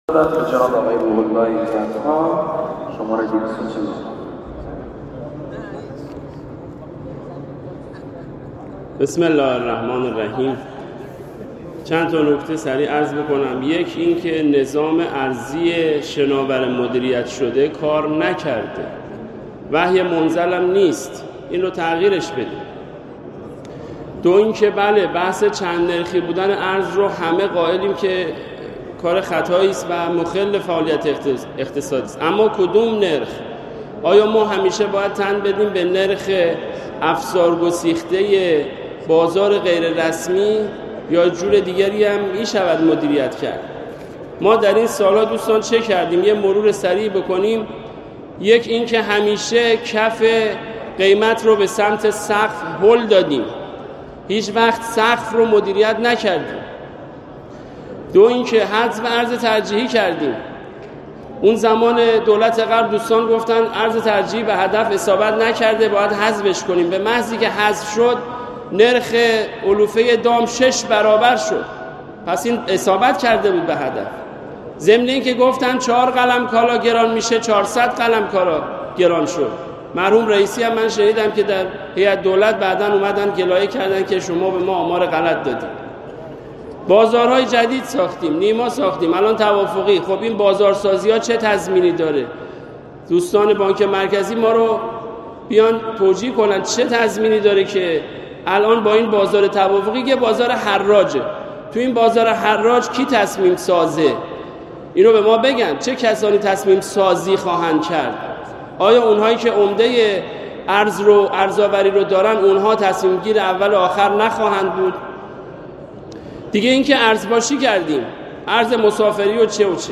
نطق دکتر ایزدخواه در صحن مجلس درباره افزایش نرخ ارز و راهکارها